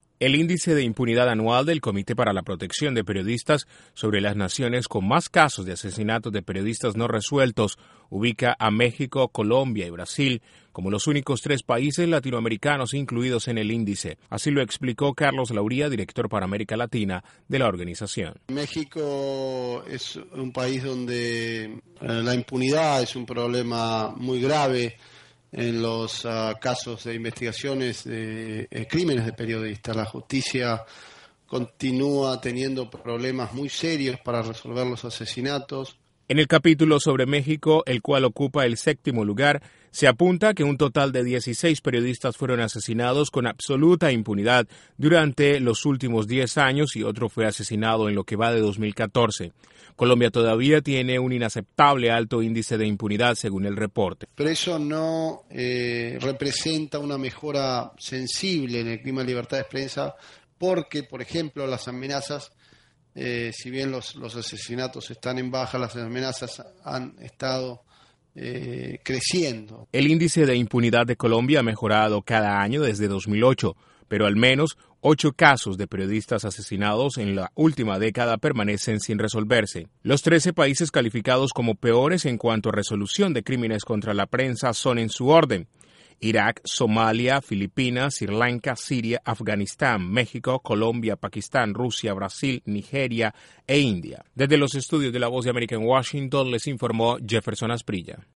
El Comité para la Protección de Periodista revelo que México, Colombia y Brasil se mantienen como los países con más impunidad por asesinato de periodistas en Latinoamérica. Desde la Voz de América en Washington informa